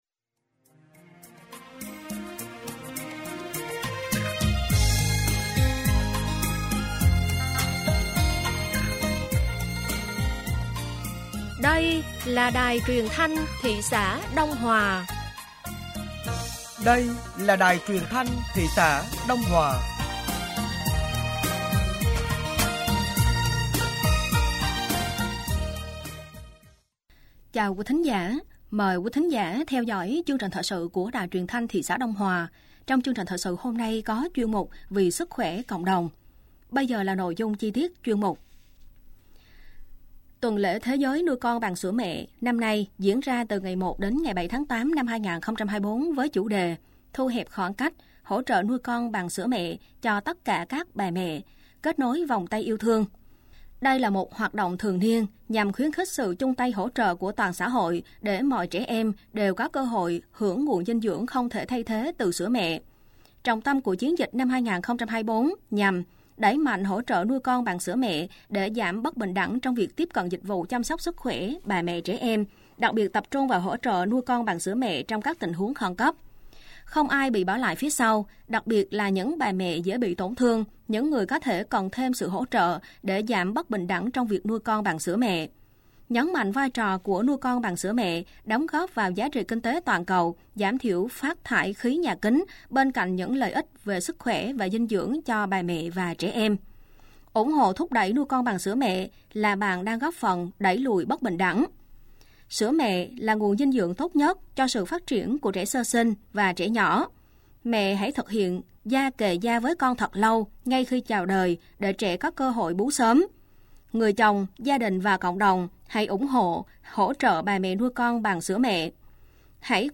Thời sự tối ngày 04 và sáng ngày 05 tháng 8 năm 2024